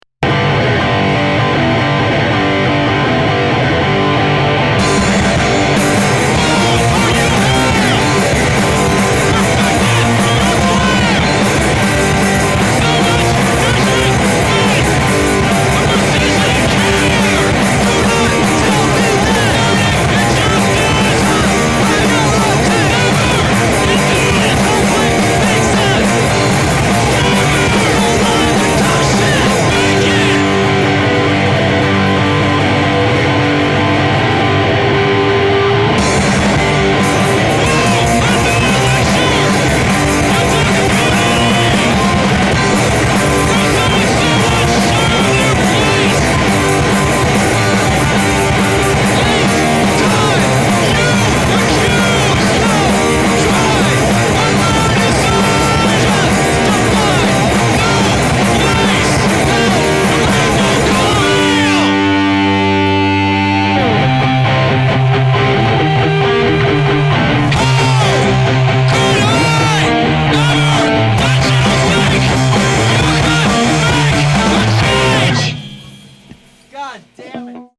Guitar/Vocals
Drums/Vocals
Filed under: Hardcore